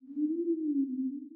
SFX_Mavka_Voice_01.wav